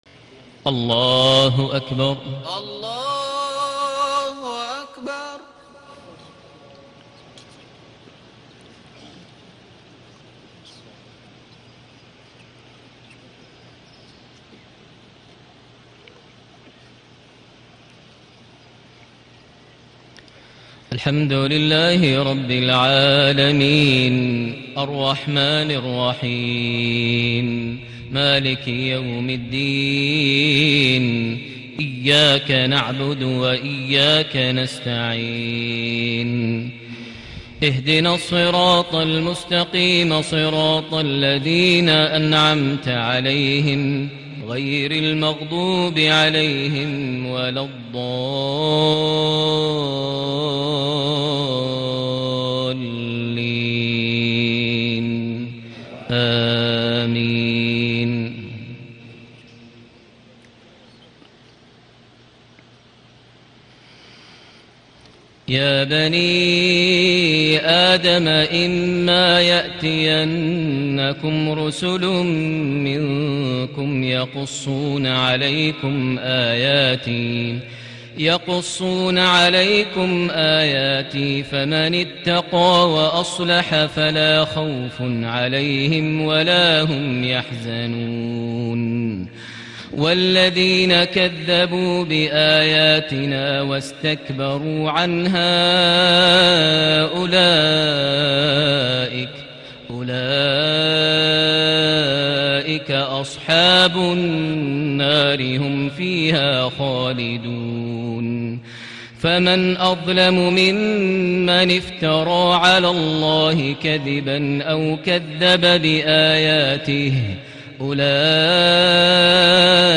صلاة العشاء ١ ربيع الأول ١٤٣٨هـ سورة الأعراف ٣٥-٤٣ > 1438 هـ > الفروض - تلاوات ماهر المعيقلي